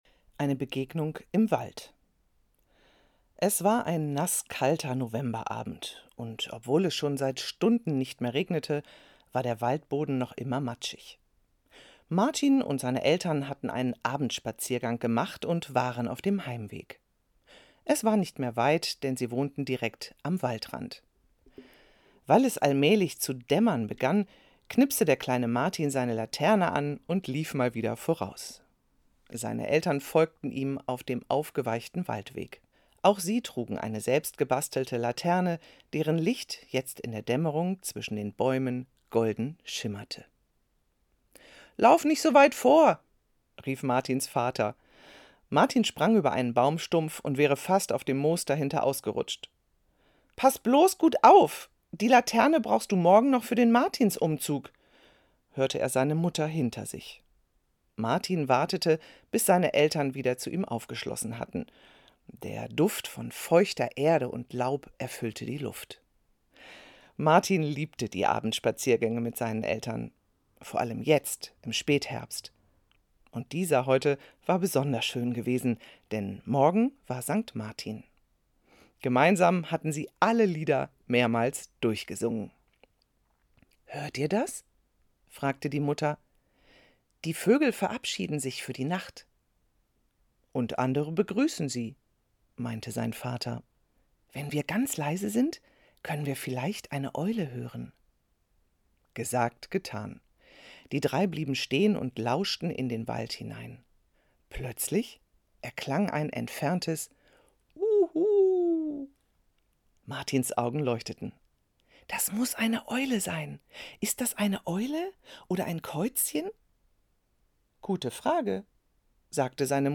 2024_SanktMartin_Vorlesegeschichte_audio.mp3